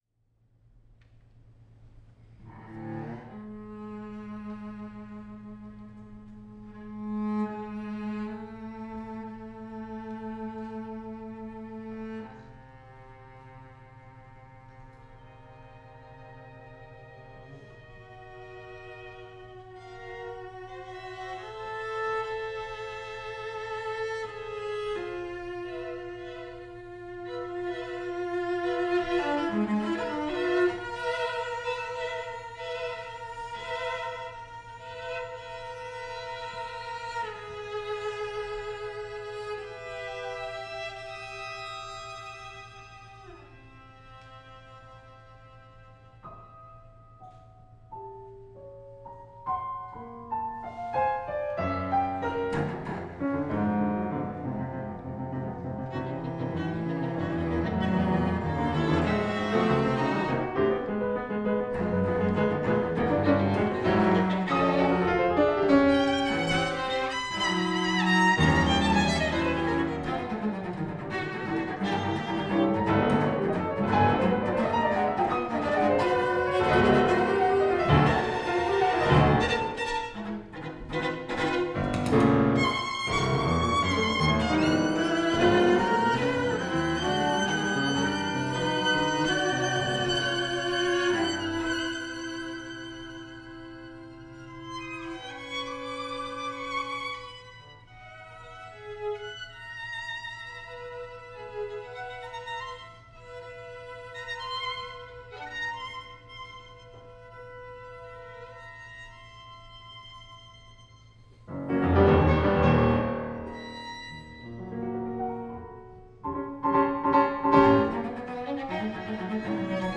chamber music recordings